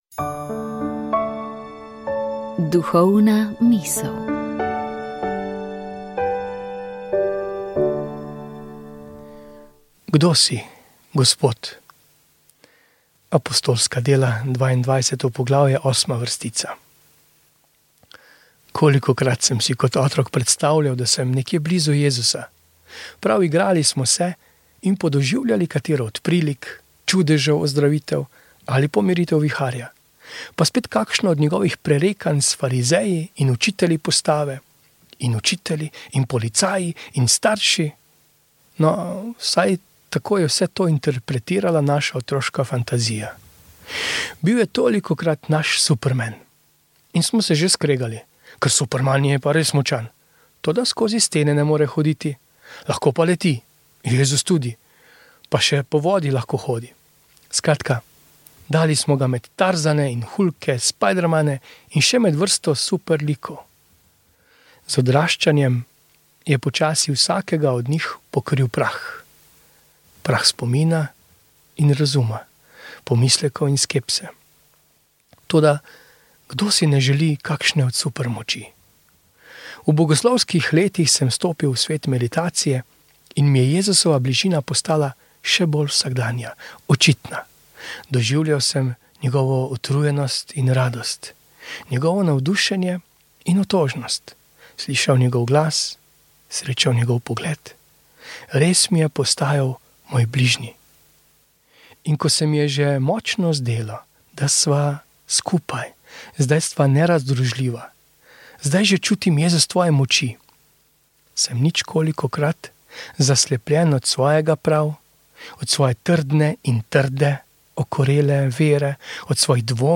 Iz župnije svete Radegunde v Starem trgu pri Slovenj Gradcu smo na trideseto nedeljo med letom neposredno prenašali sveto mašo, pri kateri so sodelovali tamkajšnji verniki.
pel pa župnijski pevski zbor.